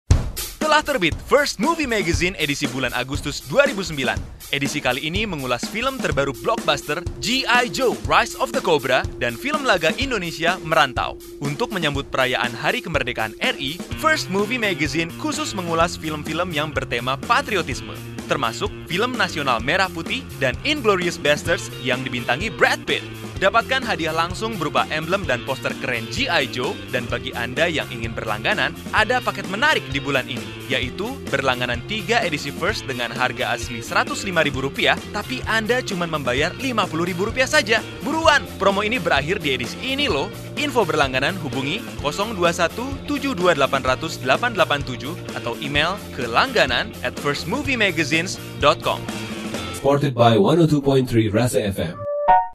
His voice has warm, clear and fresh tone.
Sprechprobe: Werbung (Muttersprache):
Indonesia voice over artist with warm, clear tone